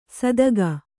♪ sadaga